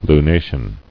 [lu·na·tion]